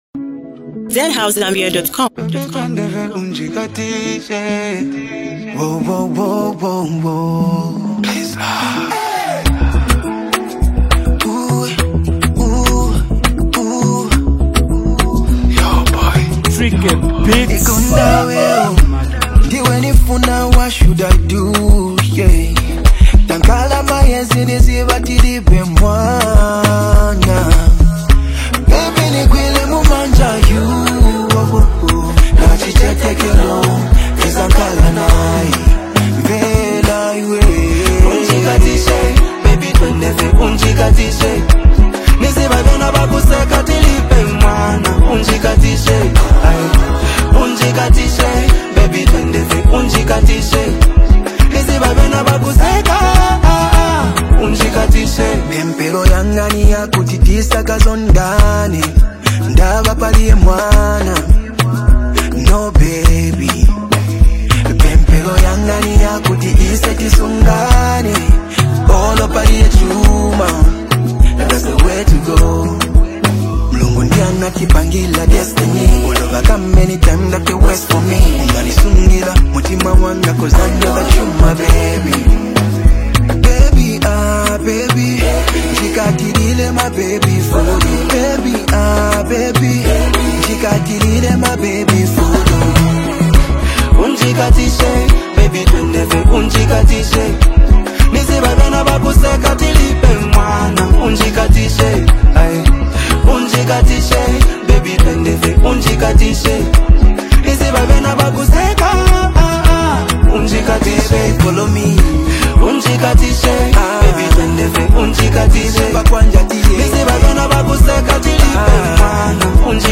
wrapped in soulful vocals and powerful melodies.